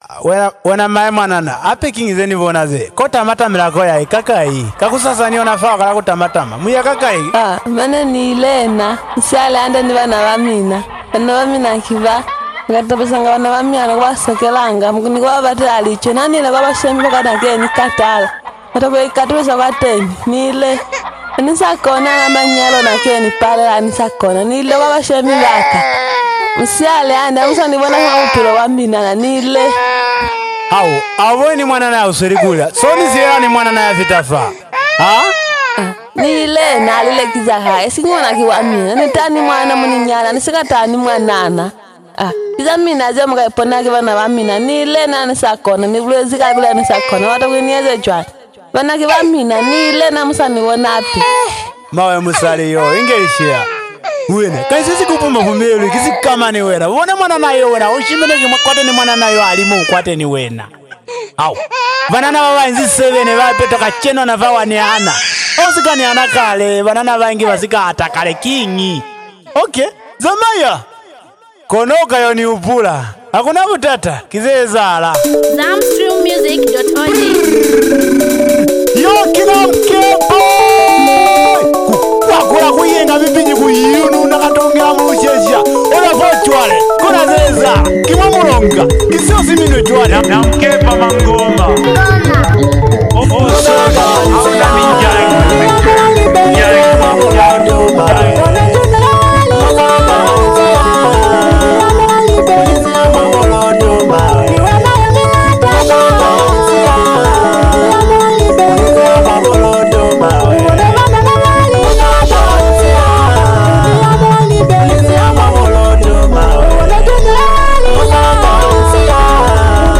a touching melody
a smooth and engaging rhythm